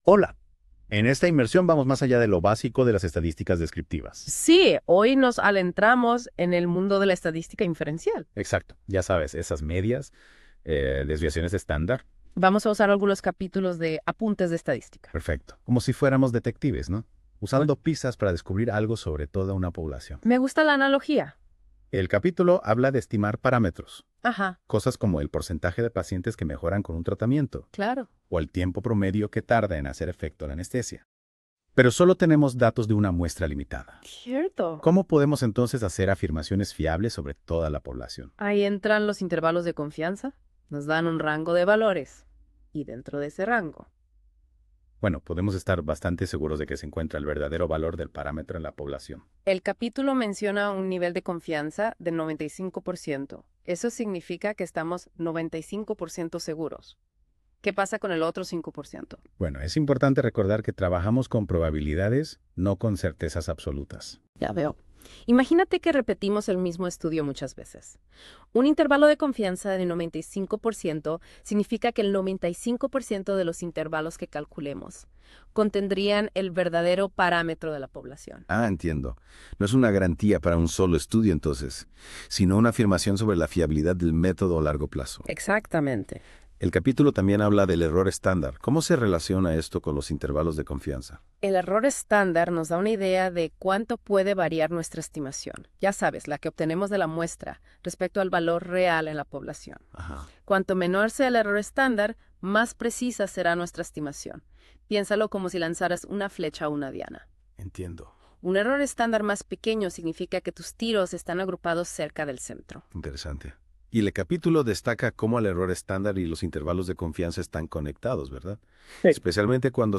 sesion2de4-resumida-porIA.m4a